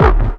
tekTTE63016acid-A.wav